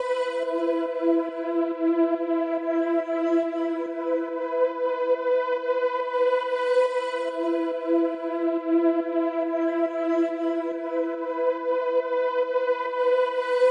VTS1 Incast Kit 140BPM Atmopad Intro.wav